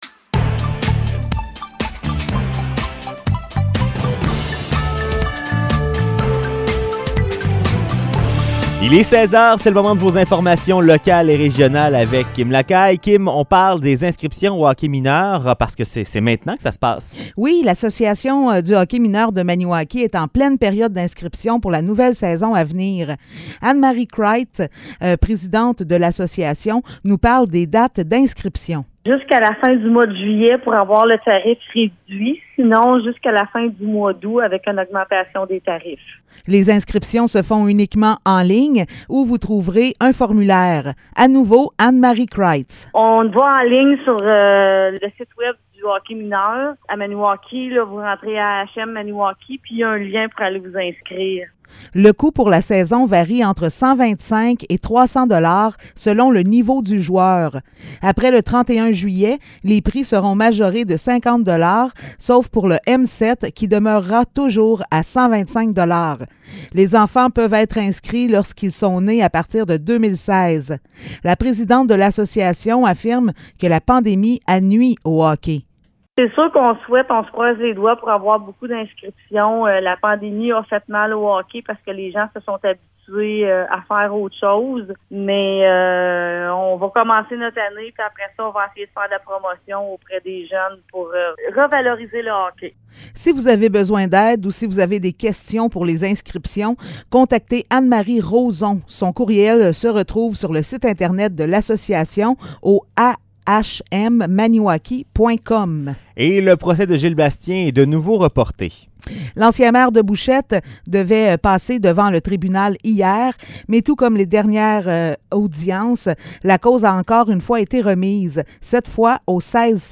Nouvelles locales - 26 juillet 2022 - 16 h